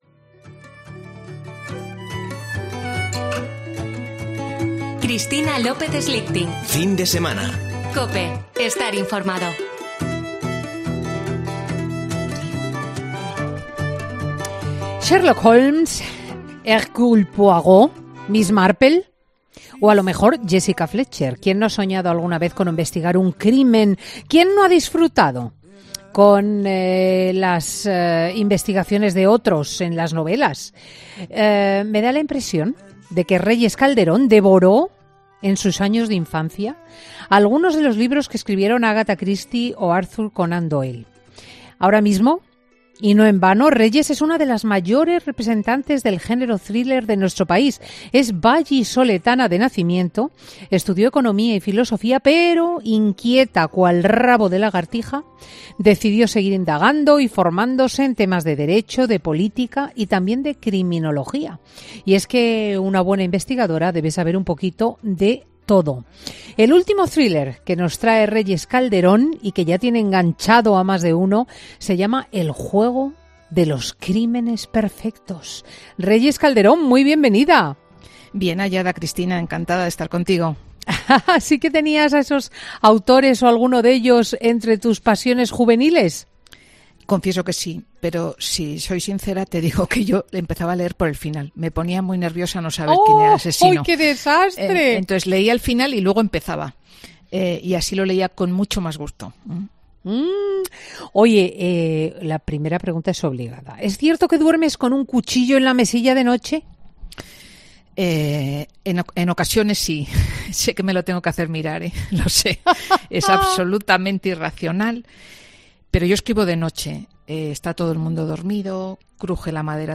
AUDIO: La escritora Reyes Calderón presenta en Fin de Semana su última novela: El juego de los crímenes perfectos